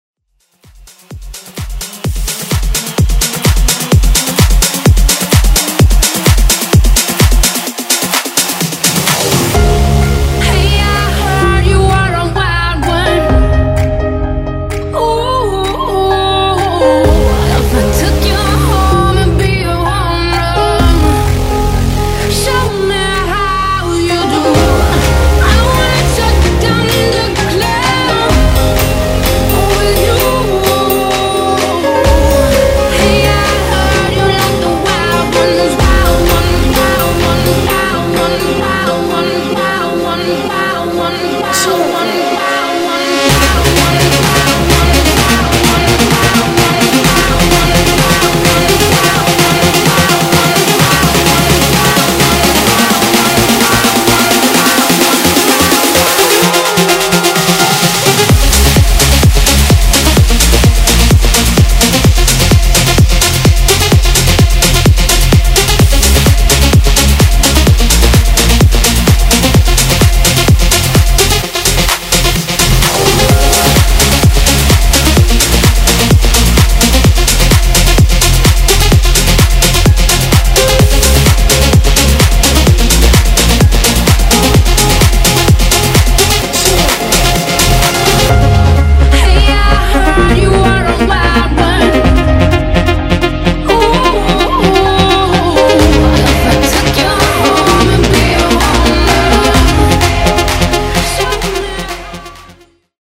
Genres: EDM , MASHUPS , TOP40
Clean BPM: 128 Time